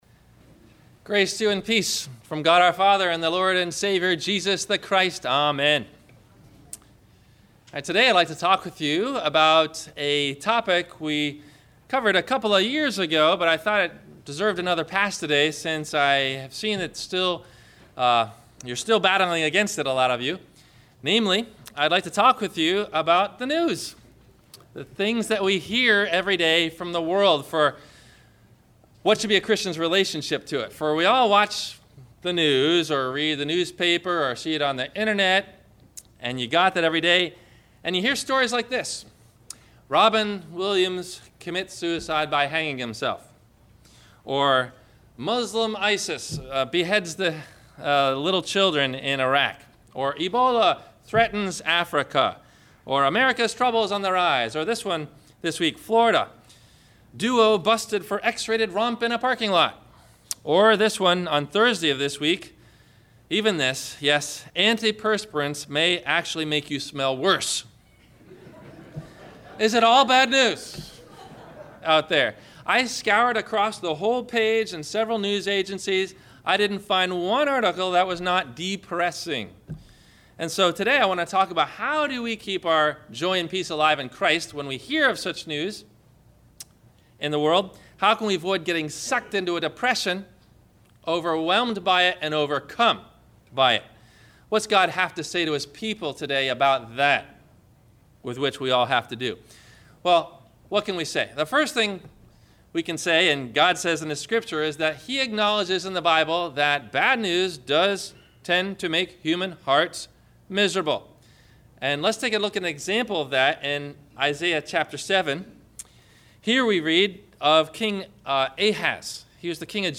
Questions answered by the Sermon: